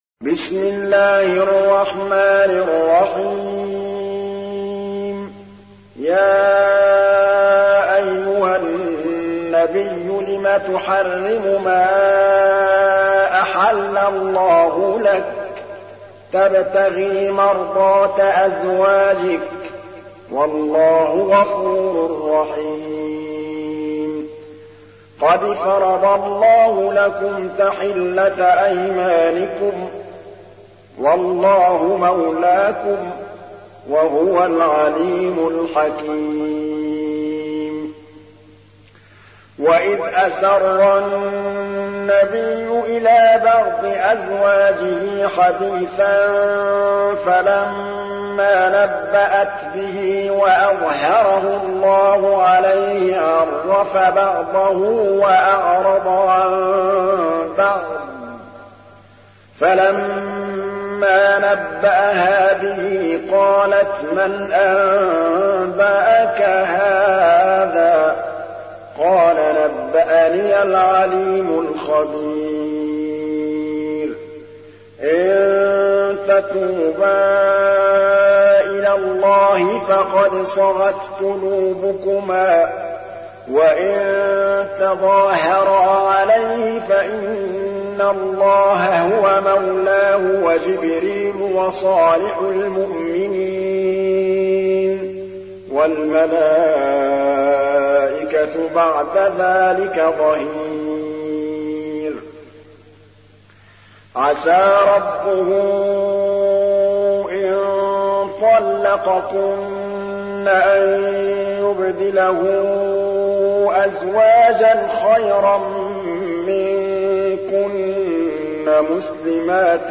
تحميل : 66. سورة التحريم / القارئ محمود الطبلاوي / القرآن الكريم / موقع يا حسين